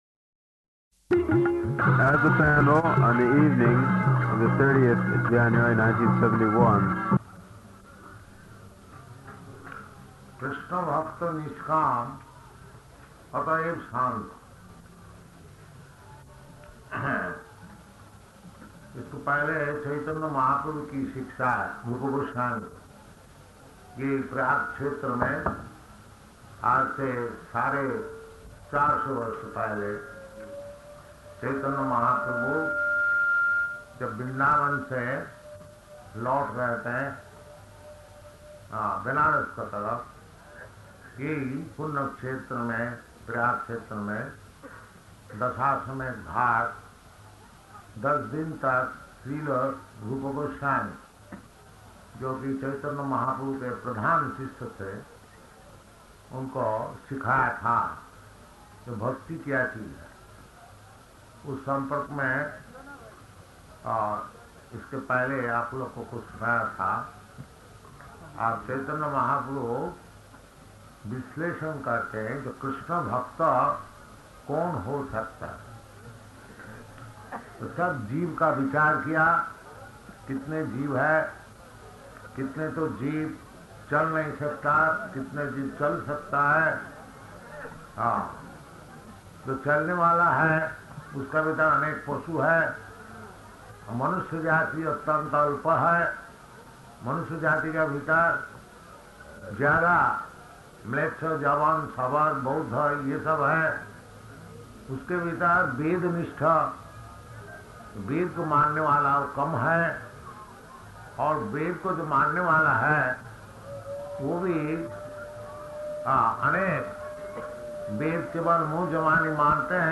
Lecture in Hindi
Lecture in Hindi --:-- --:-- Type: Other Dated: January 30th 1971 Location: Allahabad Audio file: 710130L4-ALLAHABAD.mp3 Devotee: [indistinct] on the evening on the 30th of January 1971.